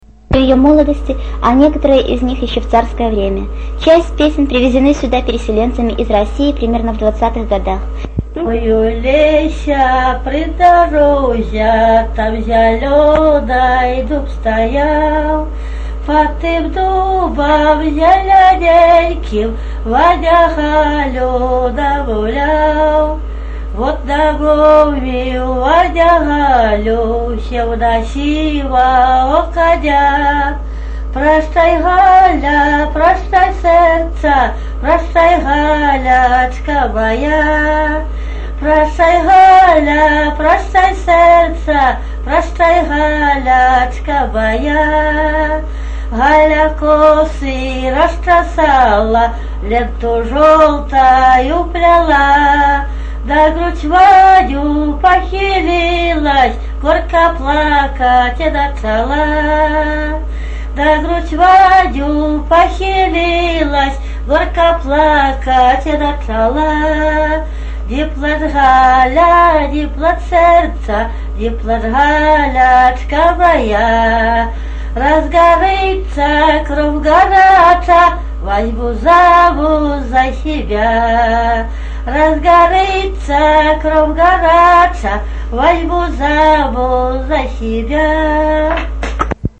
3. Эталонный фольклористический мультимедийный банк
Тема: ЭБ БГУ::Беларускі фальклор::Пазаабрадавая паэзія::любоўныя песні